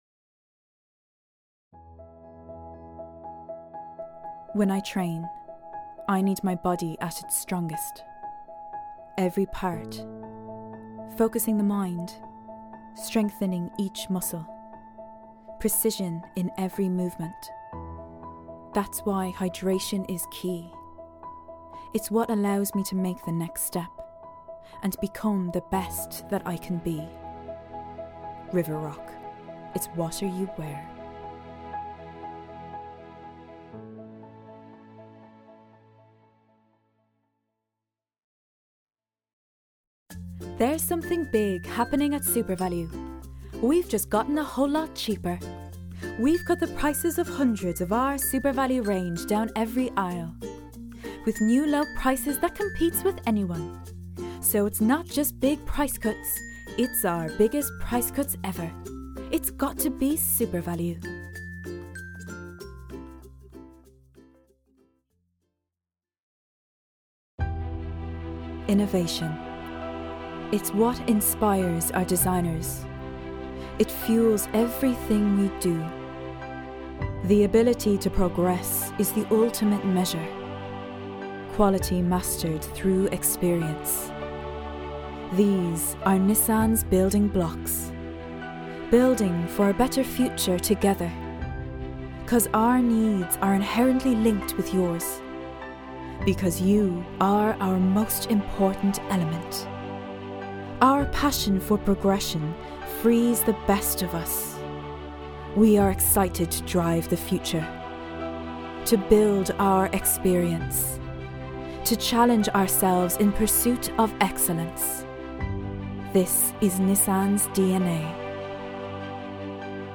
Irish voice over artist
Cool, natural and light.